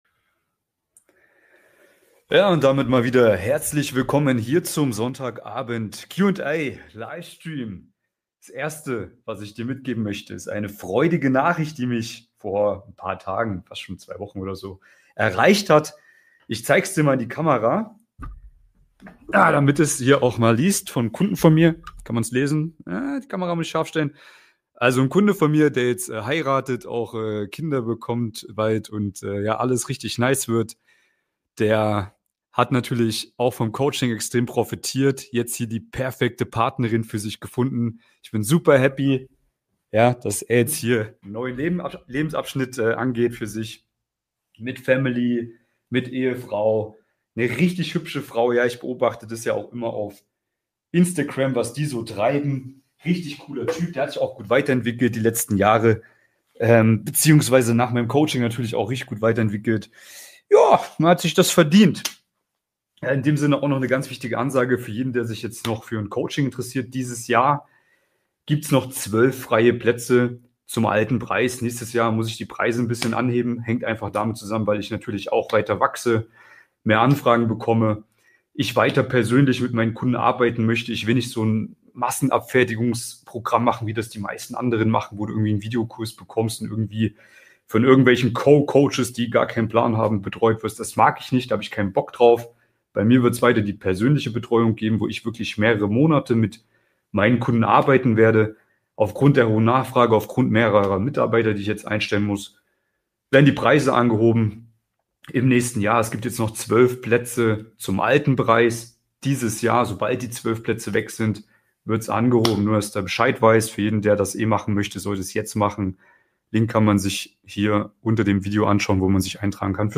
Live: Junge Partnerin finden mit der neuen Selektions-Methode!
Wenn du bereit bist, die Wahrheit über Dating zu hören, dann sei live dabei und stell mir deine Fragen.